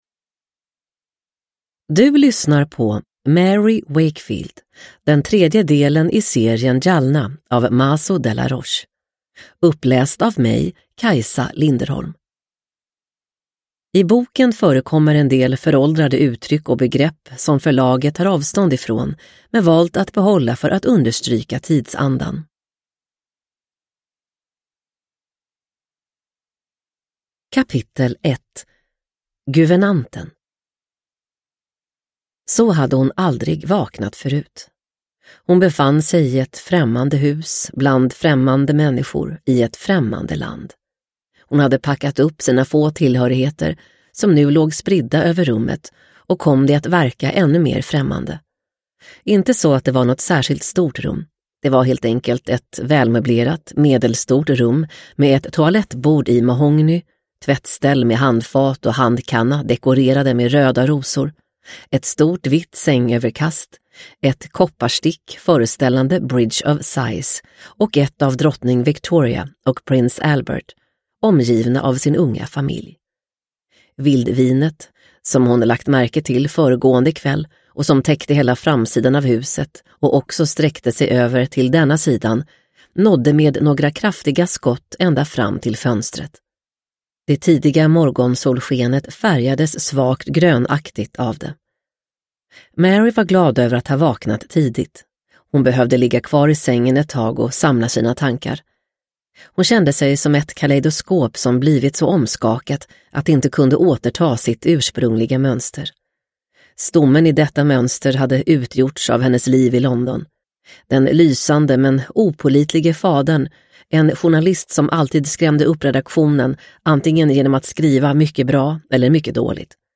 Mary Wakefield – Jalna 3 – Ljudbok – Laddas ner